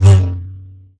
lightsaber1.ogg